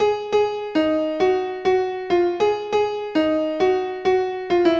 大钢琴1
描述：用三角钢琴制作的音符循环
Tag: 100 bpm Hip Hop Loops Piano Loops 833.13 KB wav Key : Unknown